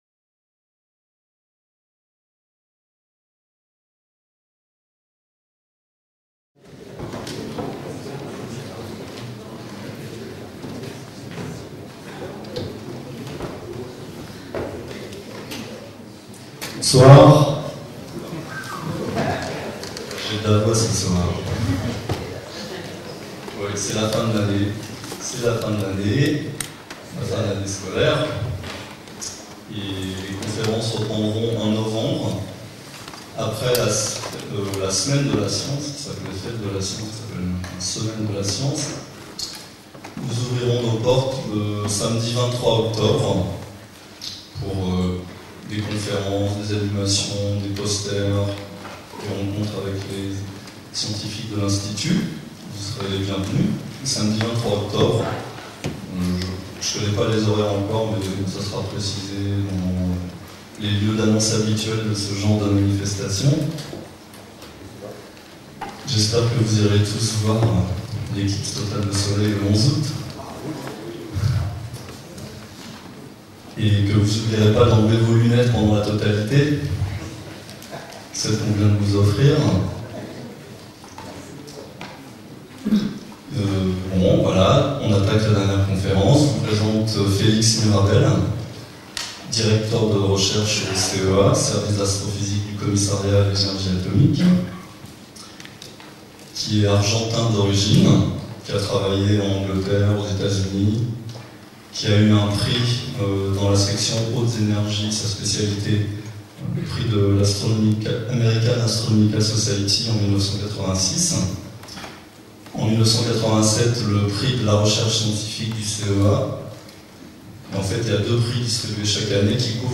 La qualité de l'image et du son de cette captation vidéo n'est pas aussi bonne qu'elle pourrait l'être si elle était réalisée aujourd'hui, mais l'intérêt qu'elle présente pour l'histoire de la diffusion des connaissances mérite sa présentation ici, à titre d'archive.